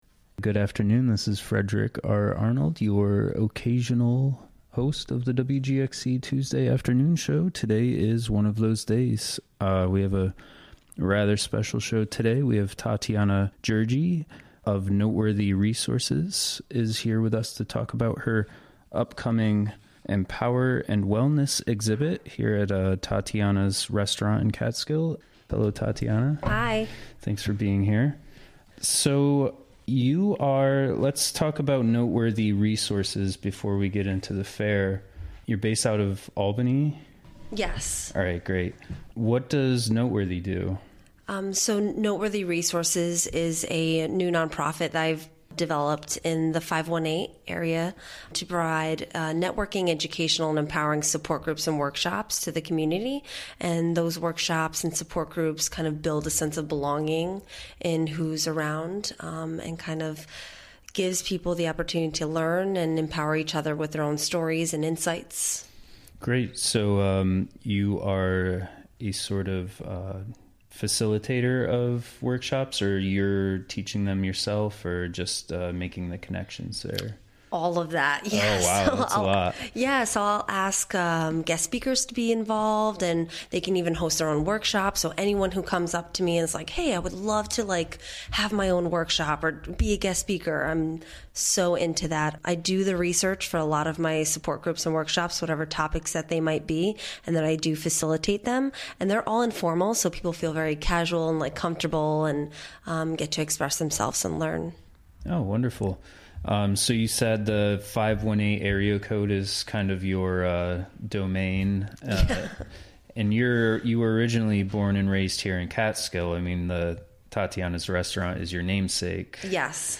Recorded during the WGXC Afternoon Show of Tuesday, Feb. 6, 2018.